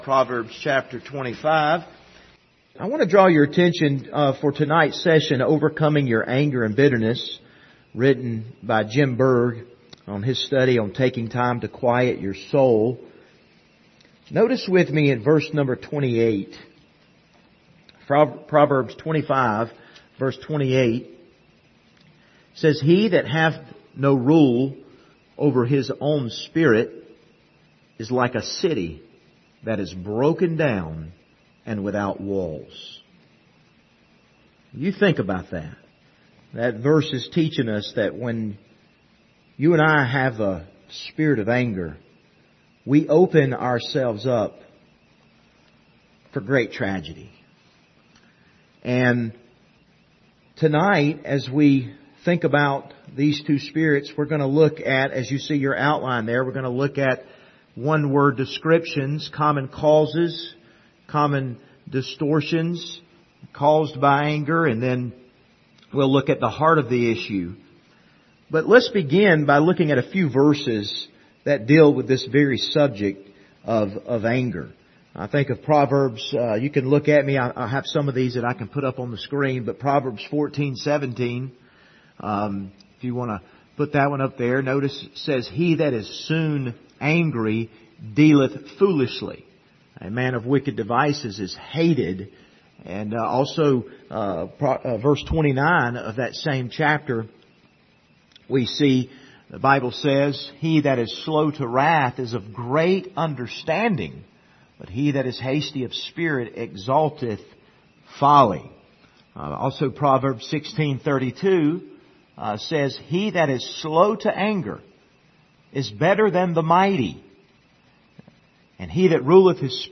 Numbers 20:1-13 Service Type: Wednesday Evening Topics